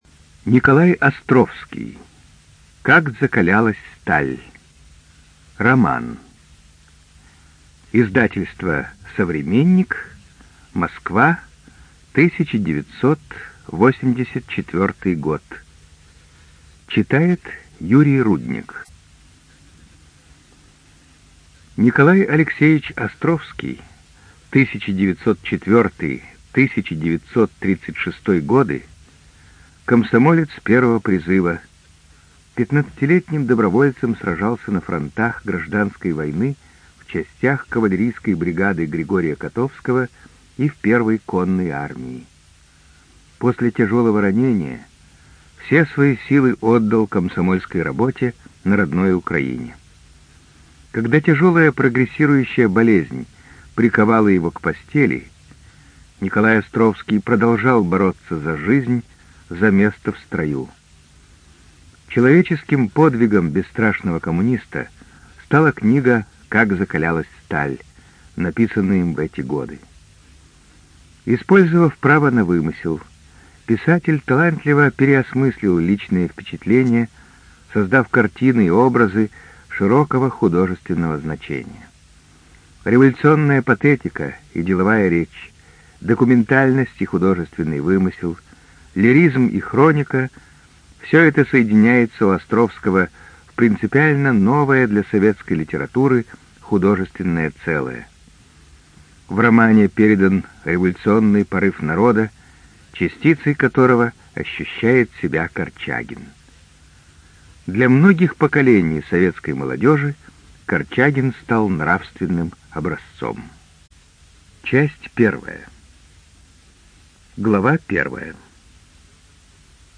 ЖанрКлассическая проза, Советская проза
Студия звукозаписиРеспубликанский дом звукозаписи и печати УТОС